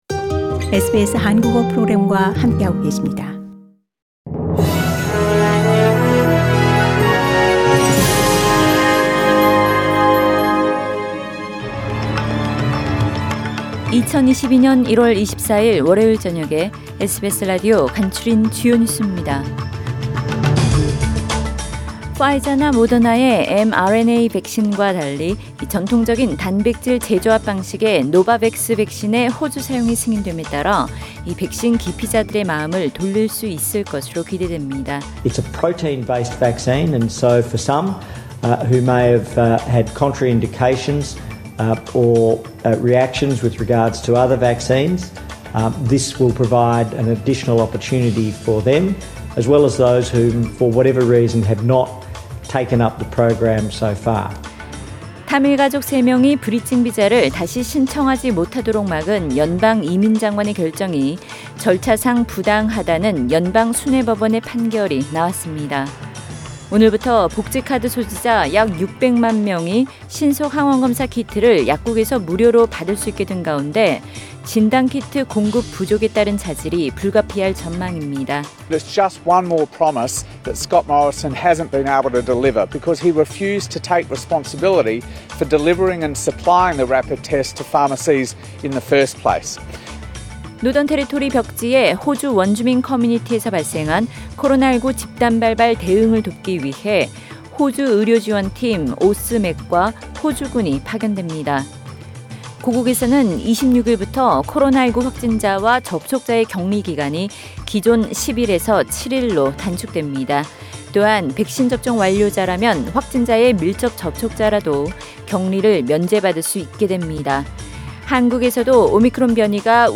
SBS News Outlines…2022년 1월 24일 저녁 주요 뉴스